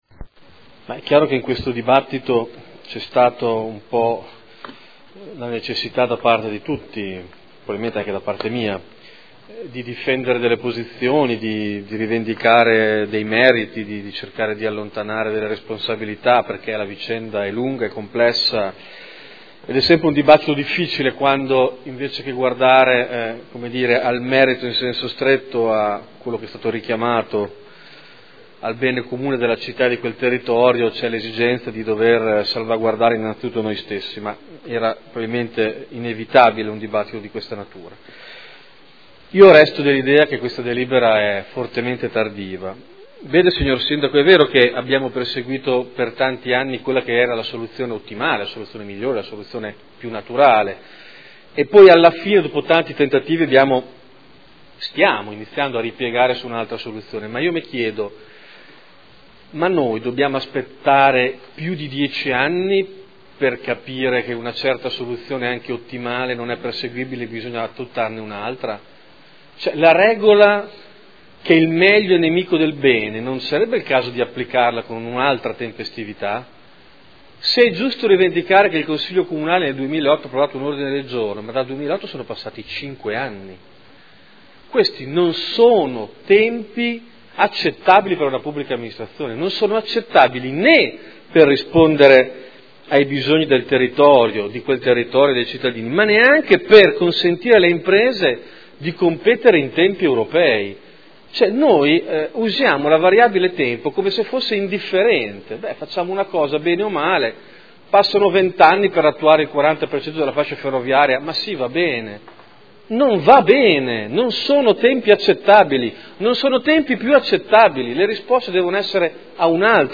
Davide Torrini — Sito Audio Consiglio Comunale